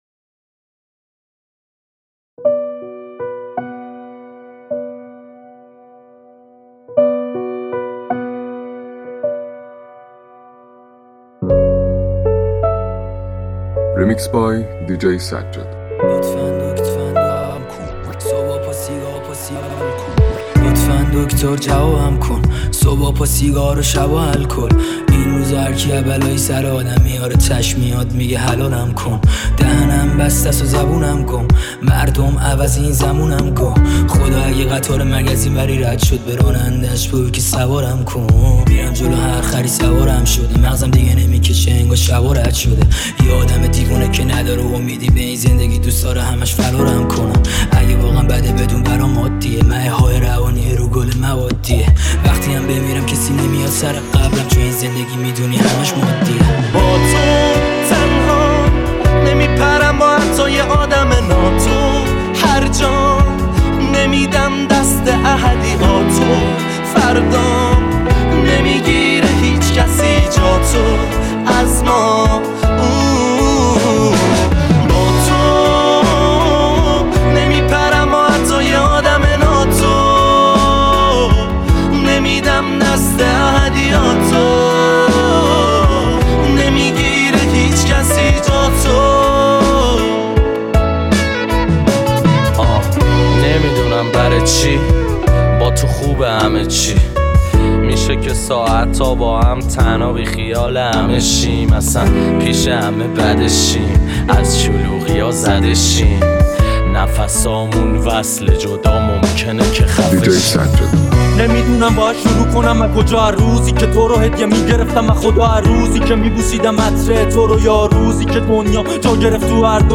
ریمیکس جدید رپ